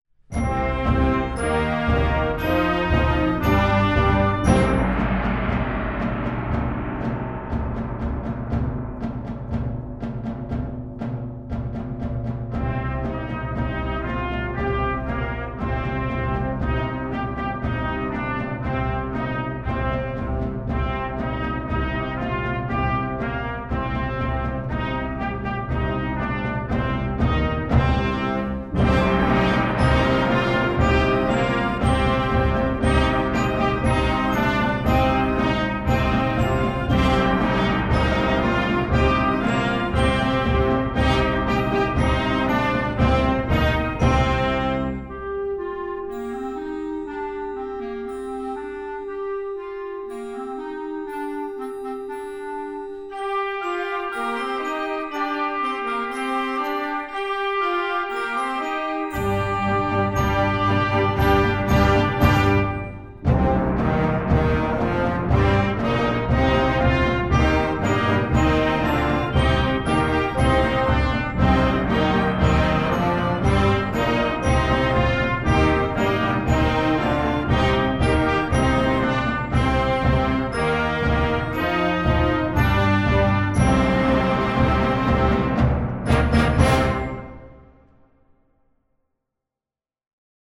Gattung: Konzertwerk für flexibles Jugendblasorchester
Besetzung: Blasorchester